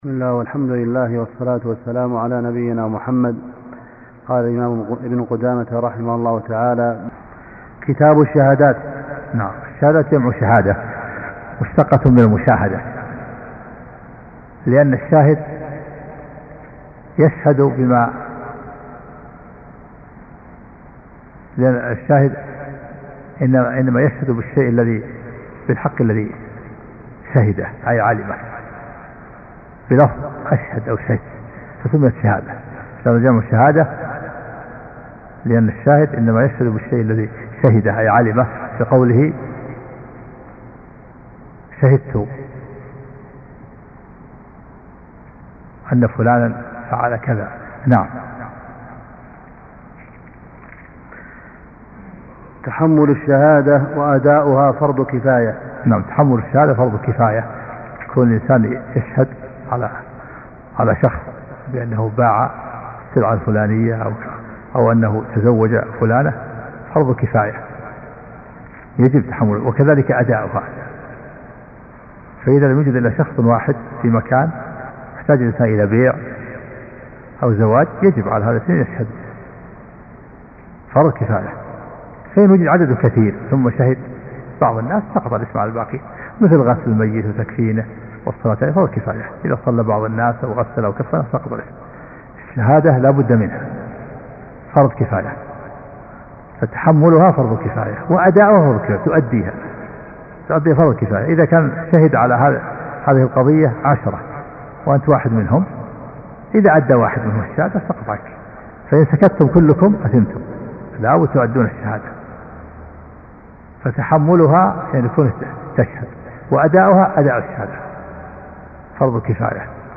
محاضرتان صوتيتان